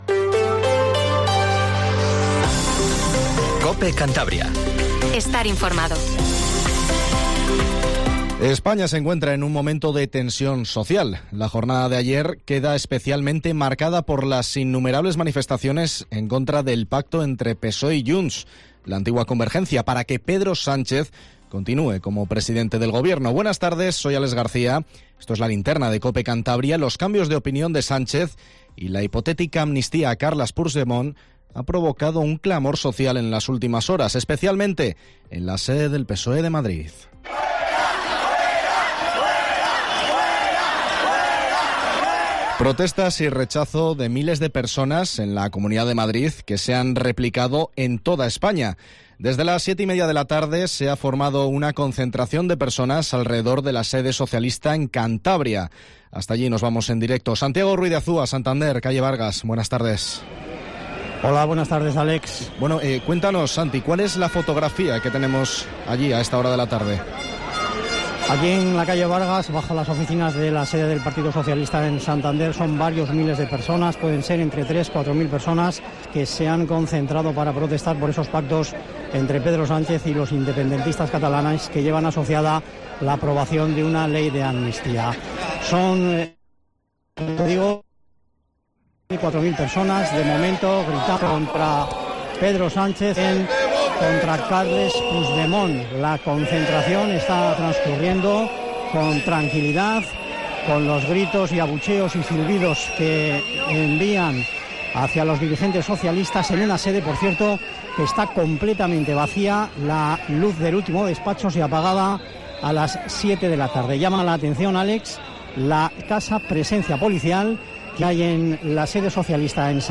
AUDIO: Nos desplazamos en directo hasta la sede del PSOE en Cantabria para conocer cuál es la situación de la concentración en contra del pacto...